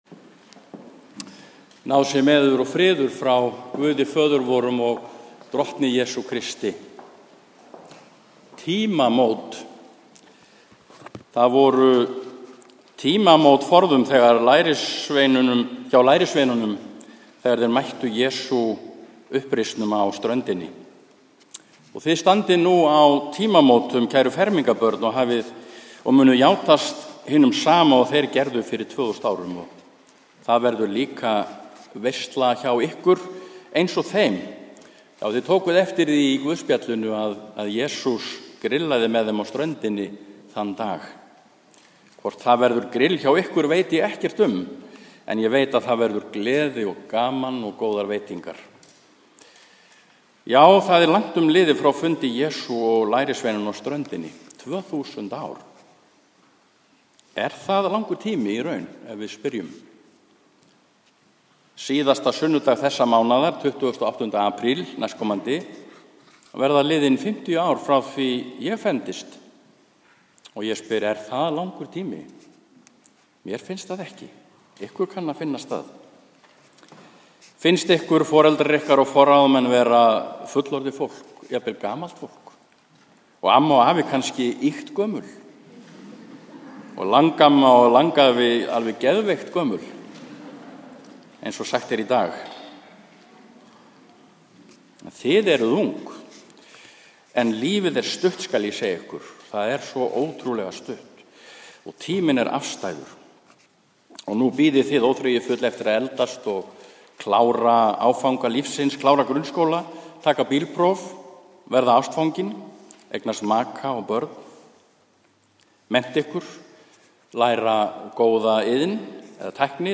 Fermingarmessa í Neskirkju sunnudaginn 7. apríl 2013 1. sd. e. páska
Flutt út frá puntkum og tekið upp og birt hér.